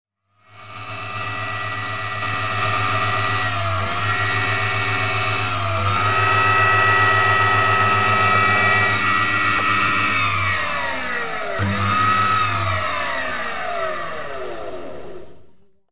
塑料袋
描述：移动塑料袋的声音
声道立体声